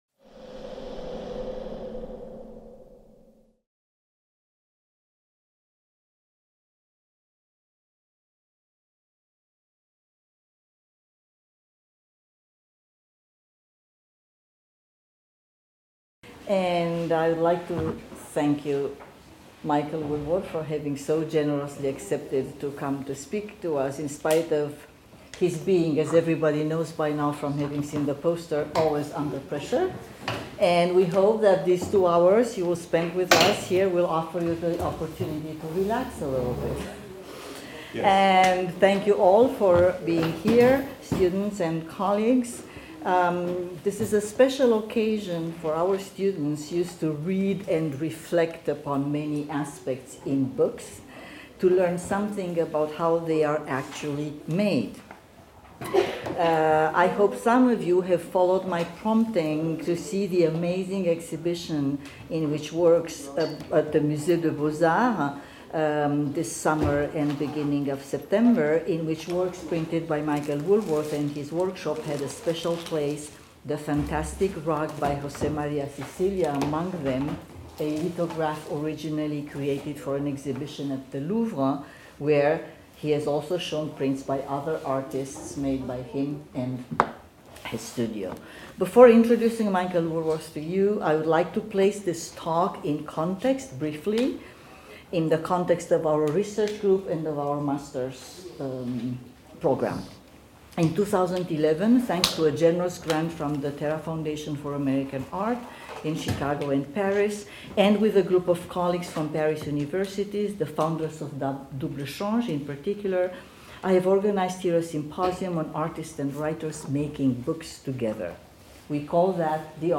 Séminaires ERIBIA 2019-2020 : Empruntes et territoires